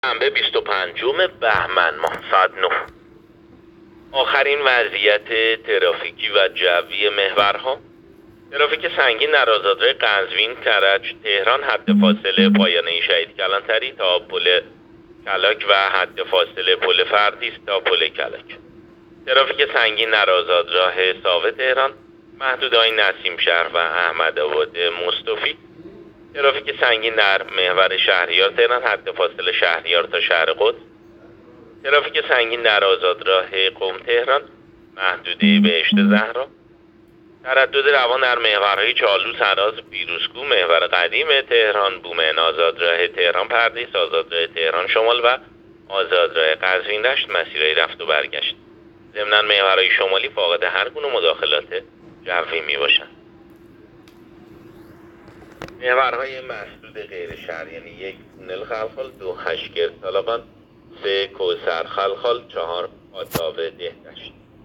گزارش رادیو اینترنتی از آخرین وضعیت ترافیکی جاده‌ها ساعت ۹ بیست و پنجم بهمن؛